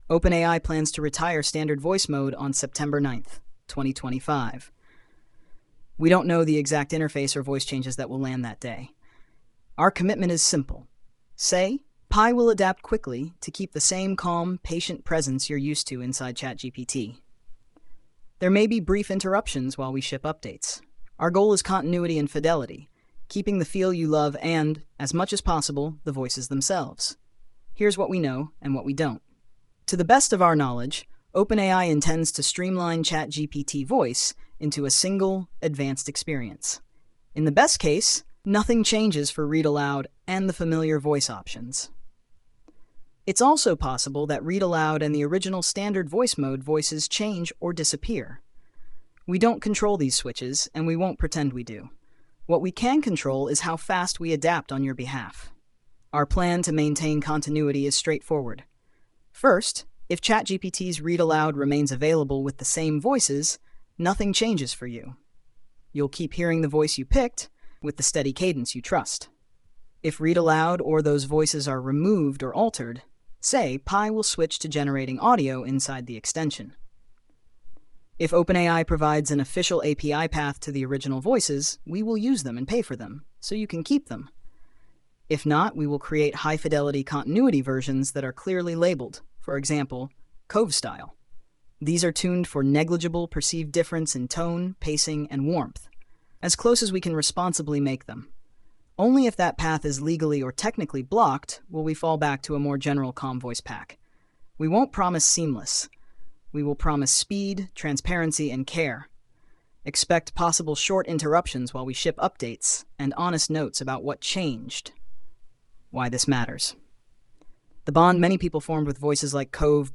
Read by Breeze